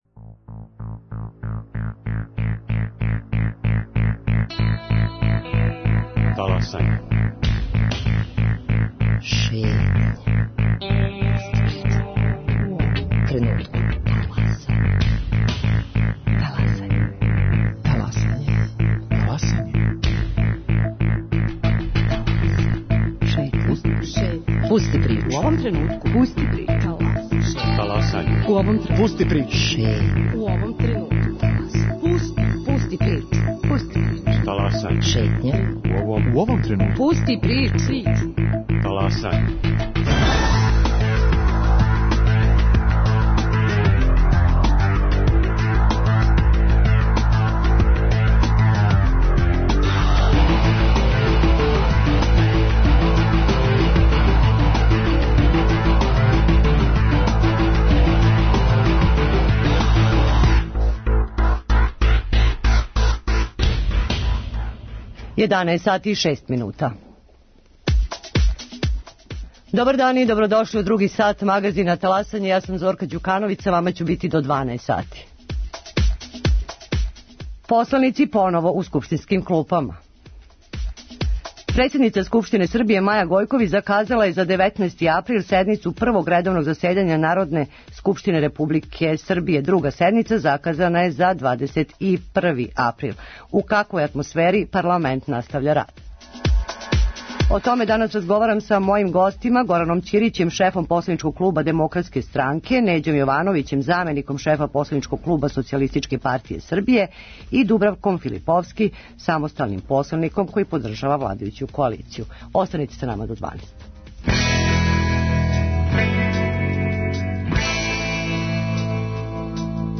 Гости Горан Ћирић, шеф посланичког клуба Демократске странке, Неђо Јовановић, заменик шефа посланичког клуба СПС и Дубравка Филиповски, самостални посланик.